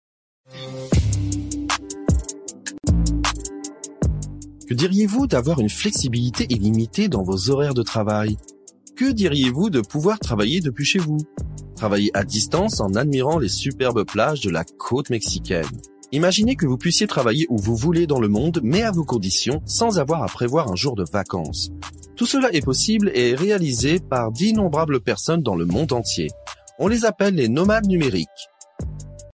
法语样音试听下载
法语配音员（男1） 法语配音员（男2）